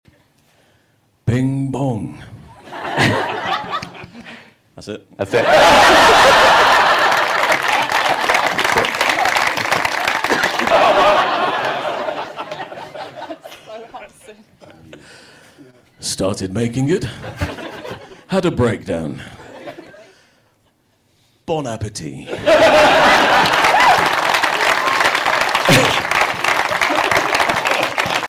Context: The BG3 cast were told they were going to play a game where they had to read quotes in their character’s voice.
Footage from the Baldur’s Gate 3 BAFTA Masterclass Panel